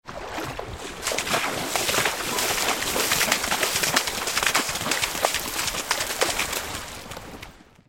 دانلود آهنگ دریا 12 از افکت صوتی طبیعت و محیط
دانلود صدای دریا 12 از ساعد نیوز با لینک مستقیم و کیفیت بالا